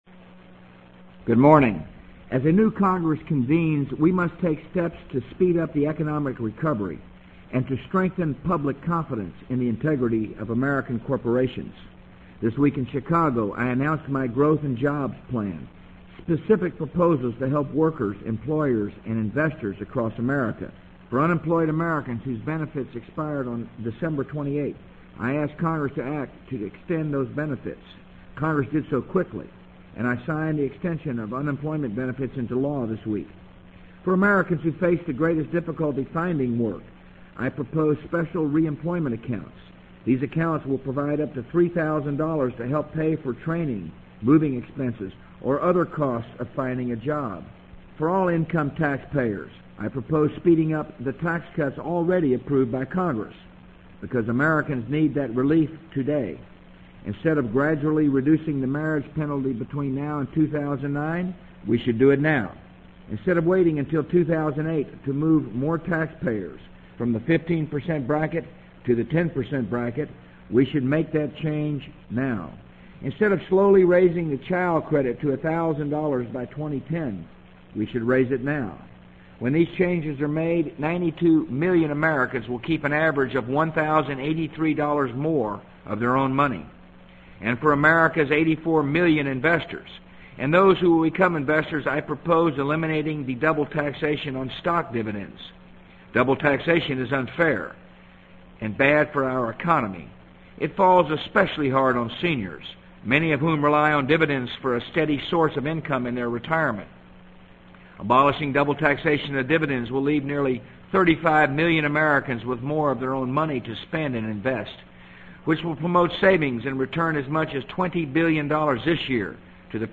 【美国总统George W. Bush电台演讲】2003-01-11 听力文件下载—在线英语听力室